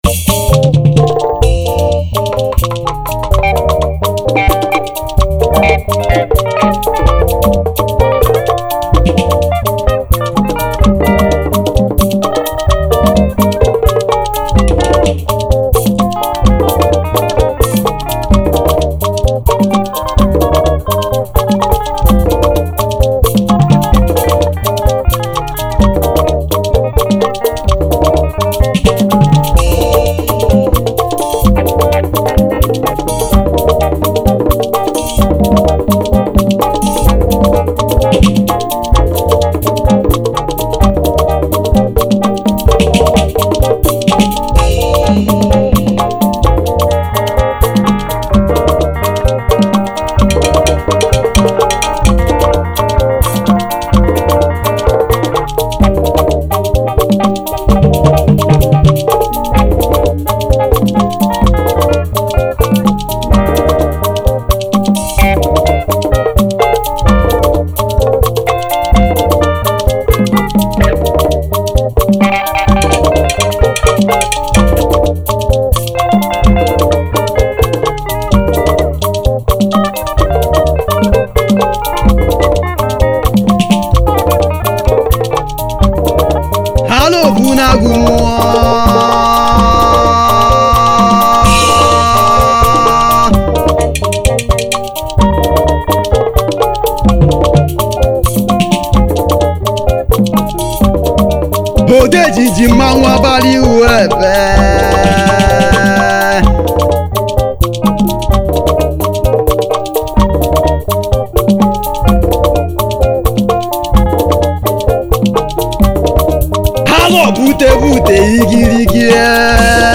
Highlife Traditional